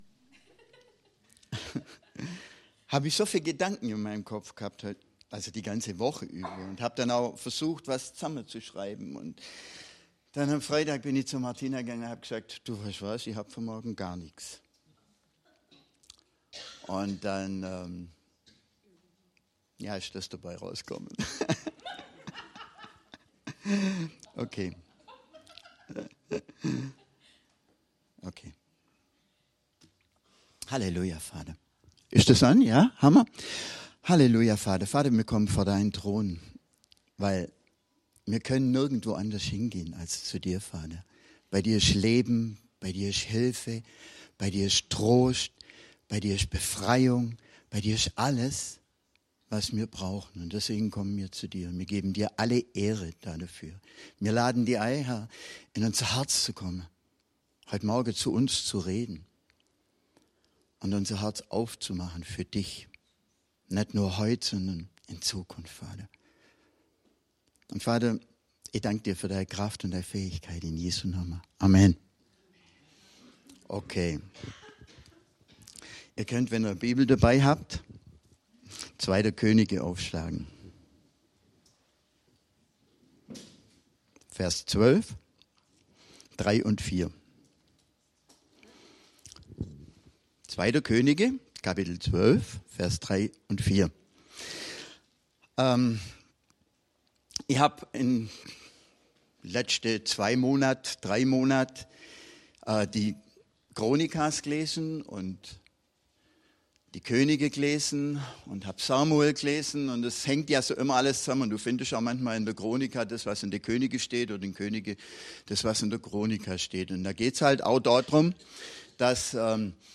Predigt vom 24.11.2024 – Christliches Zentrum Günzburg